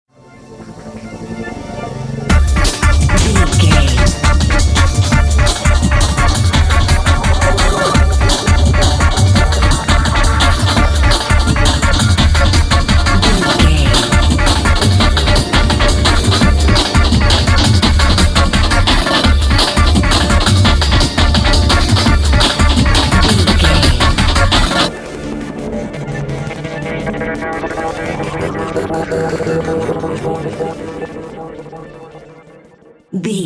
Dark Drum and Bass.
Aeolian/Minor
D
Fast
aggressive
powerful
groovy
futuristic
industrial
frantic
drum machine
synthesiser
break beat
electronic
sub bass